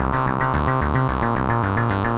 Index of /musicradar/8-bit-bonanza-samples/FM Arp Loops
CS_FMArp A_110-C.wav